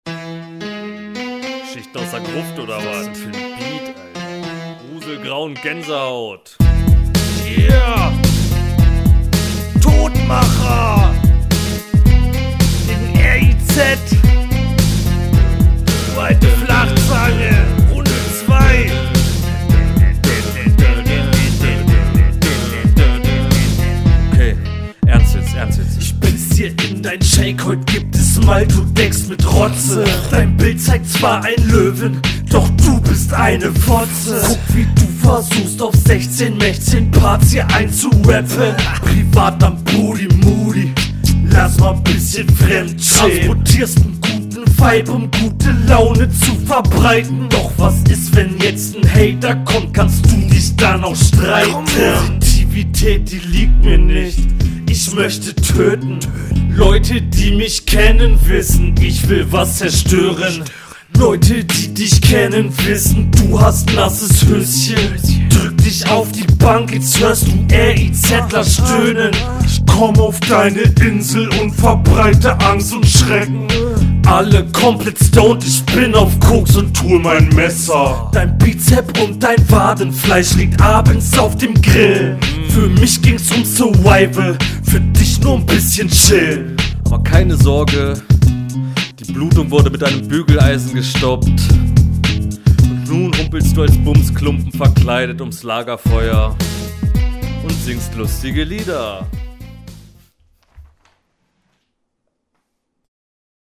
Beatwahl find ich persönlich mega fail.
Kommst hier mit einem recht unspektakulärem Zick Zack Flow.
Der Beat ist Whack, aber die Runde ist witzig.